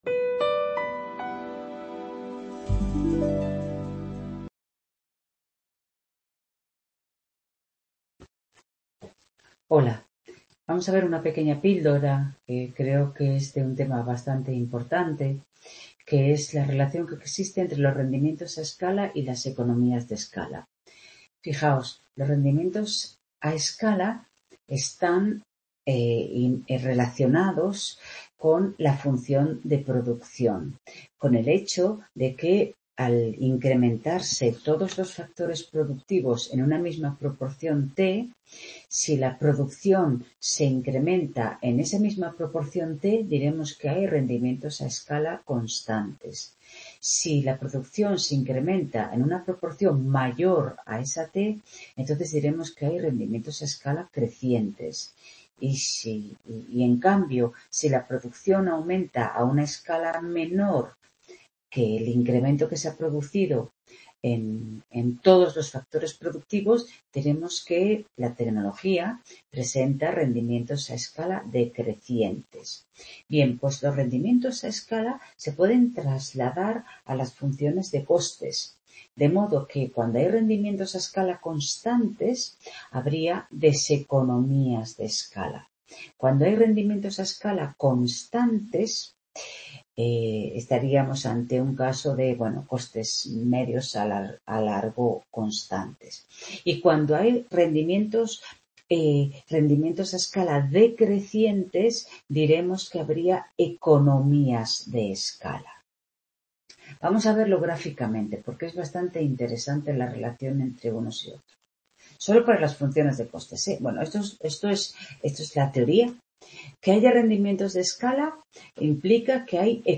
Esta es una pequeña píldora que he grabado durante la tutoría de Microeconomía de 2º de ADE del día 7/11/2022. En ella relaciono los rendimientos a escala de las funciones de producción y su equivalente en economías de escala en las funciones de costes.
Video Clase